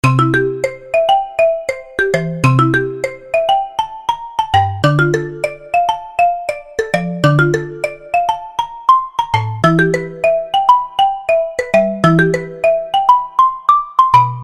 Kategori Marimba